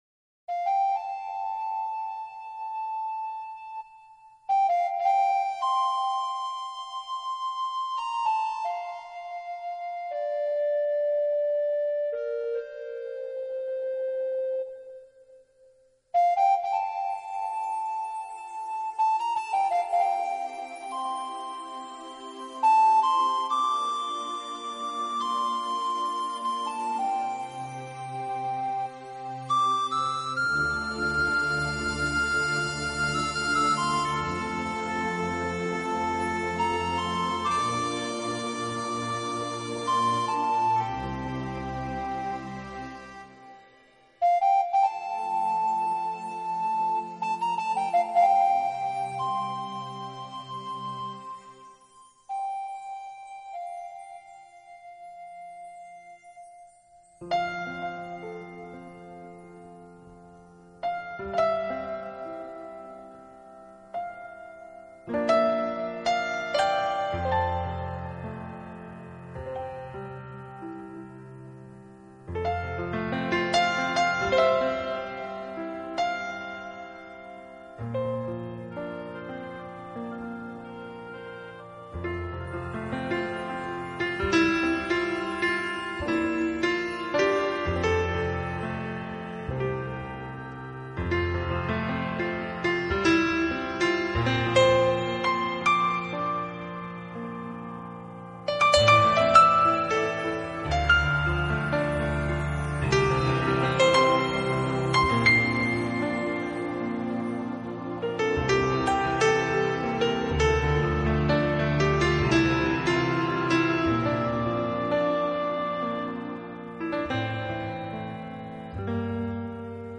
新世纪钢琴
音乐风格: Newage